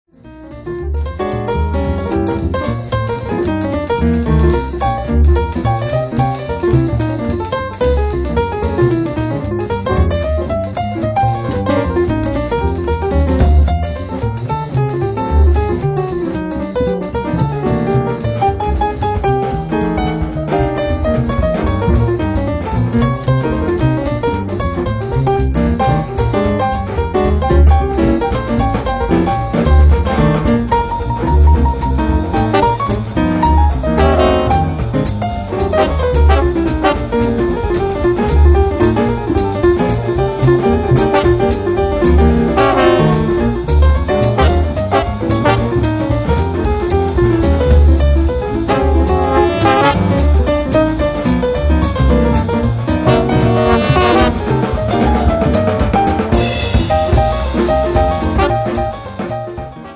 tenor sax & clarinet